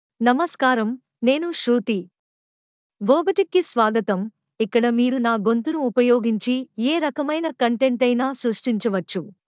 FemaleTelugu (India)
Shruti — Female Telugu AI voice
Voice sample
Female
Shruti delivers clear pronunciation with authentic India Telugu intonation, making your content sound professionally produced.